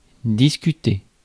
Ääntäminen
IPA: [dis.ky.te]